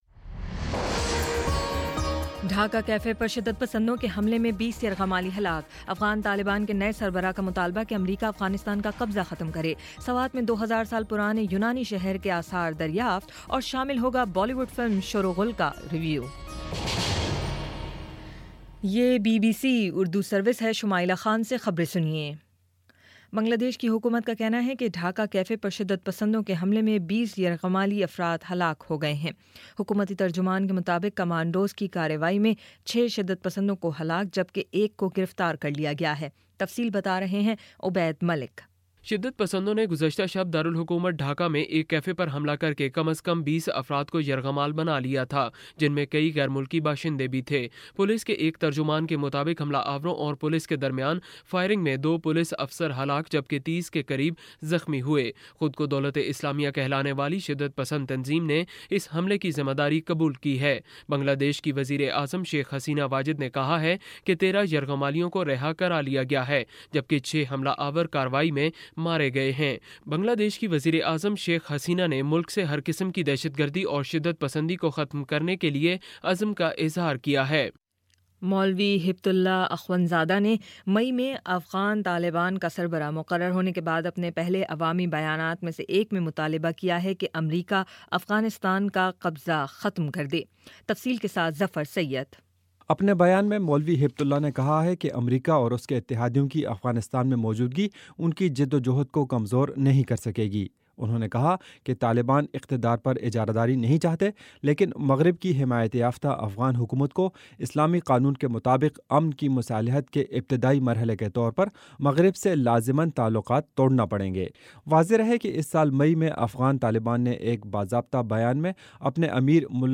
جولائی 02 : شام پانچ بجے کا نیوز بُلیٹن